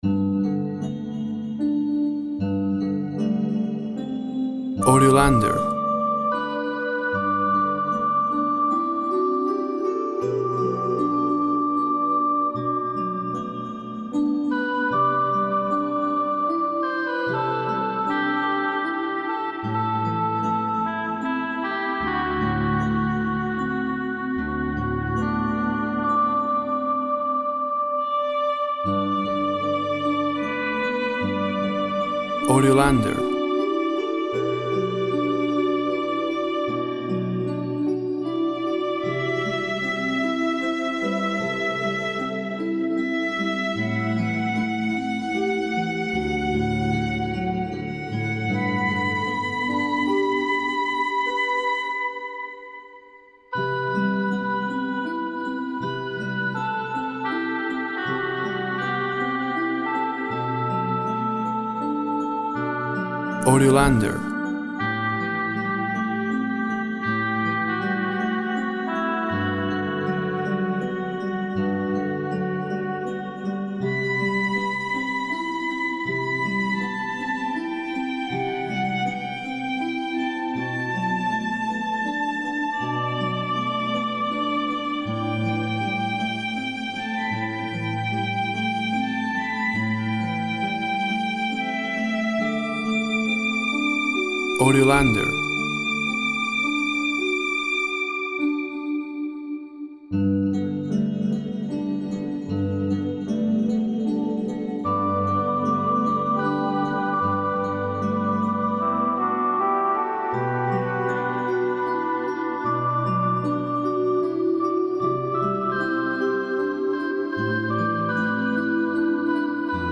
Harp, reed, and violin sing of sadness and longing.
Tempo (BPM) 76/78